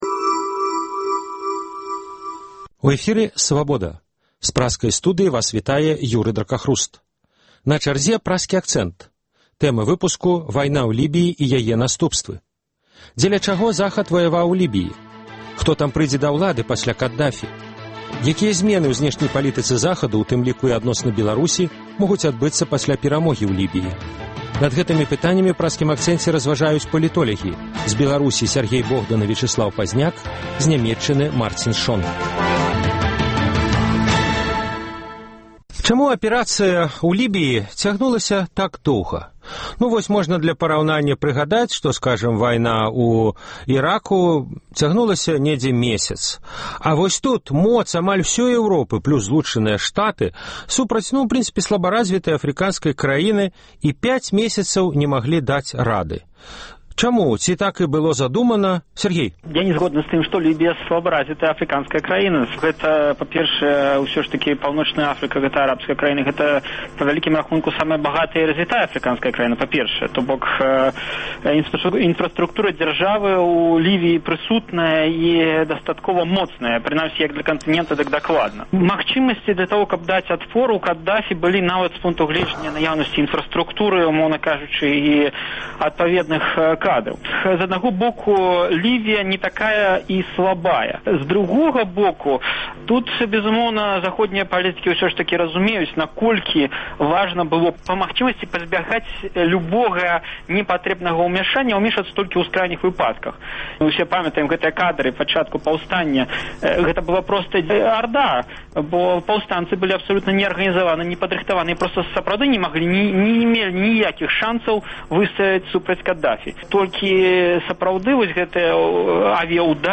Дзеля чаго Захад ваяваў у Лібіі? Хто там прыйдзе да ўлады пасьля Кадафі? Якія зьмены ў зьнешняй палітыцы Захаду, у тым ліку і адносна Беларусі, могуць адбыцца пасьля перамогі ў Лібіі? Удзельнічаюць палітолягі